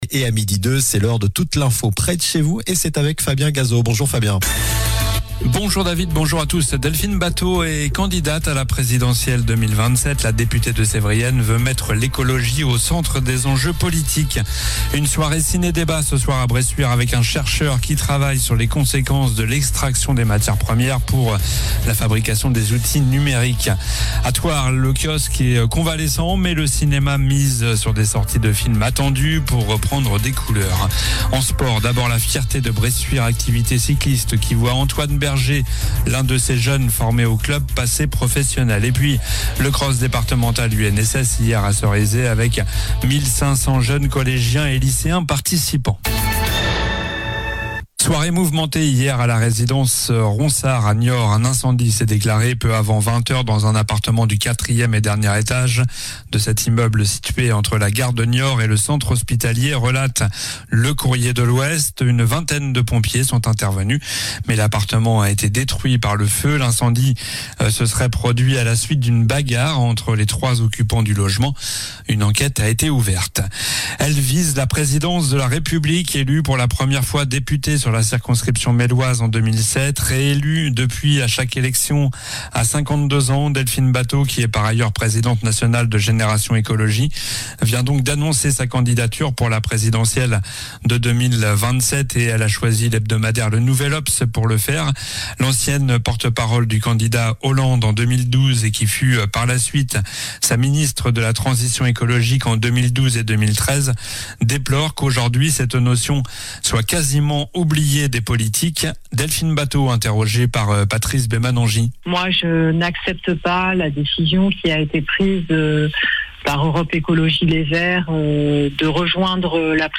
Journal du jeudi 27 novembre (midi)